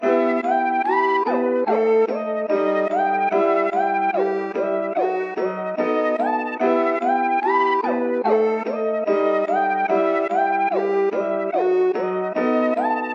标签： 146 bpm Breakbeat Loops Percussion Loops 1.11 MB wav Key : Unknown
声道立体声